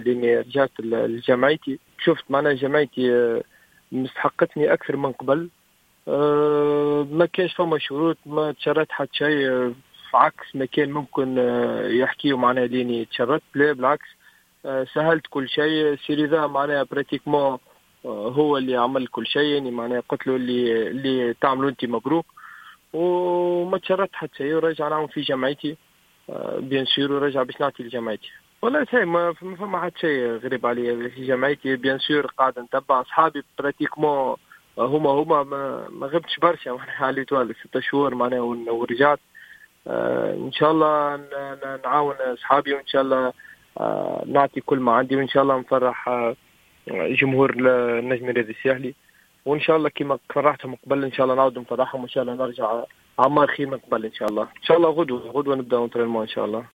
أكد اللاعب عمار الجمل في تصريح لجوهرة أف أم أنه سعيد بالعودة إلى فريقه الأم النجم الساحلي بعد أن أمضى عقدا اليوم لمدة سنتين و نصف مضيفا أنه شعر أن فريقه بحاجة إليه وهو ما جعله يعطي موافقته للهيئة المديرة دون وضع أي شروط للتعاقد.